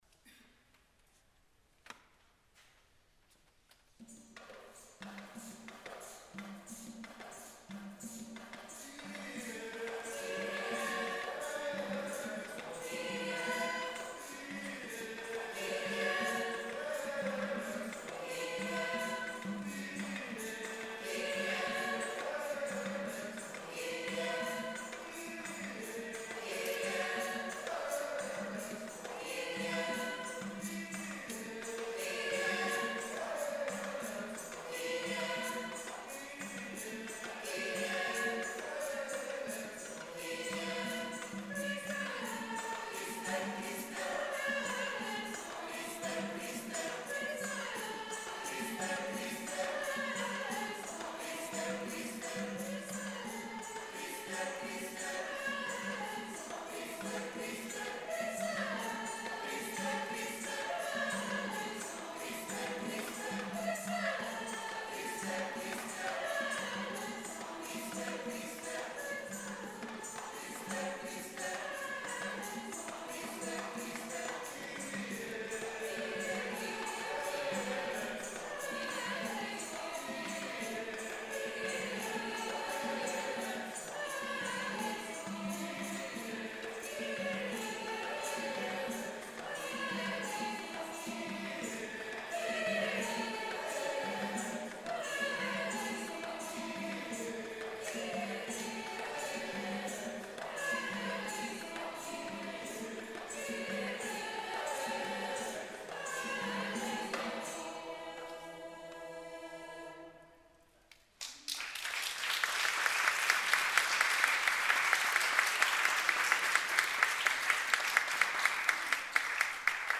Programma di canti natalizi polifonici gospel & spirituals
presso a Cappella dell'ospedale "Regina Apostolorum"
dalla messa Missa Luba in stile congolese per coro misto e percussioni arrangiata da Guido Haazen